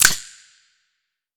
TC3Snare15.wav